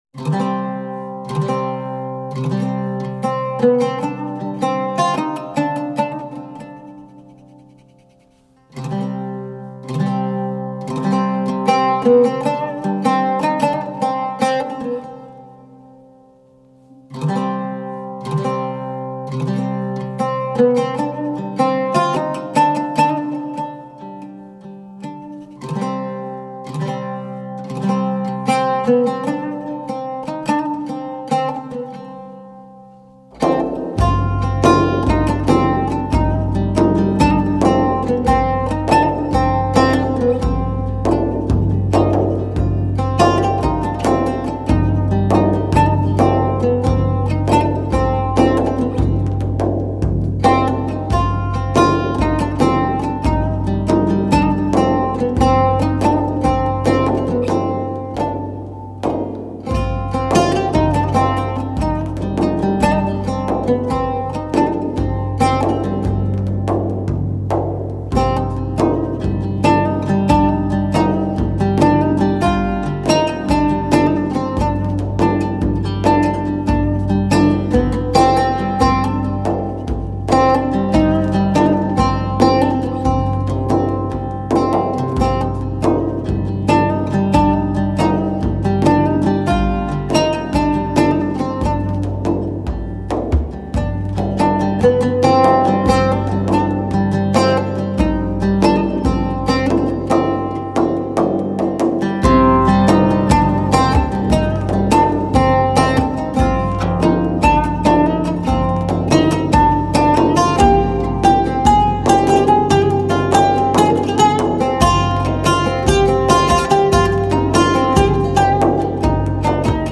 beautiful traditional piece from the Armenian repertoire
is played on Iraqi oud and bass tar.